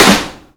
Waka SNARE ROLL PATTERN (71).wav